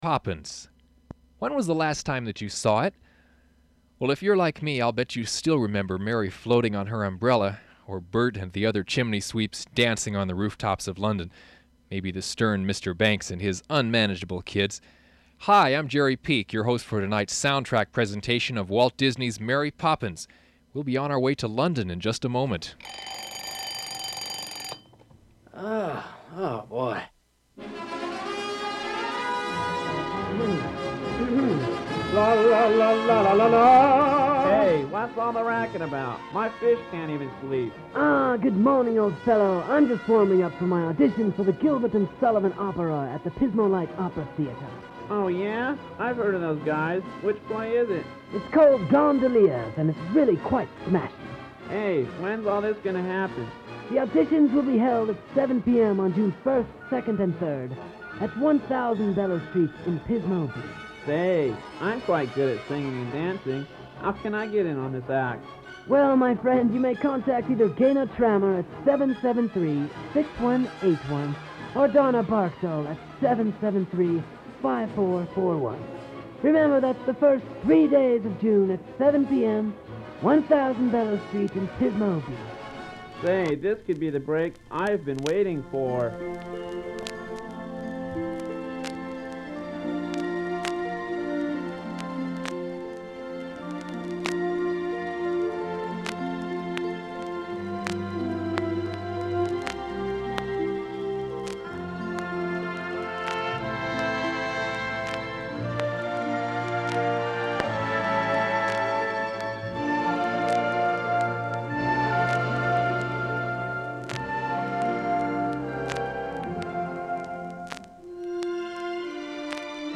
Open reel audiotape